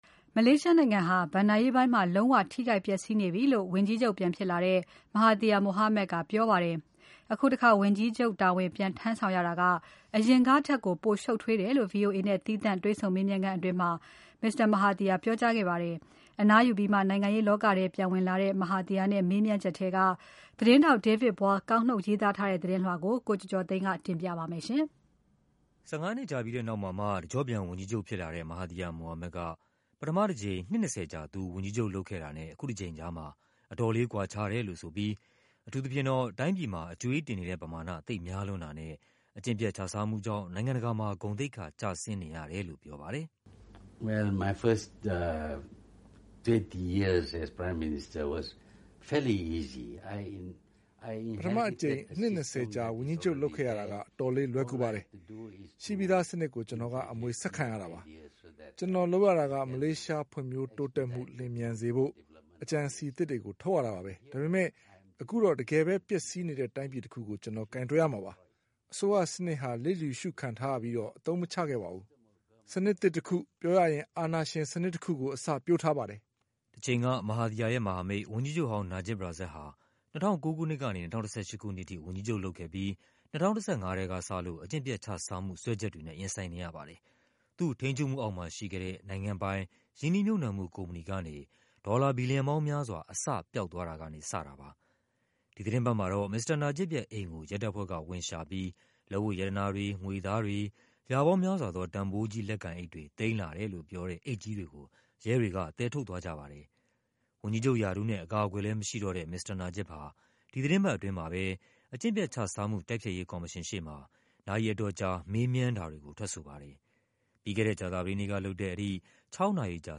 မေလ ၂၄ ရက်၊ ကြာသပတေးနေ့က ဗွီအိုအေနဲ့ သီးခြားတွေ့ဆုံမေးမြန်းခန်းအတွင်း မစ္စတာမဟာသီယာက အဲဒီလို ပြောသွားတာပါ။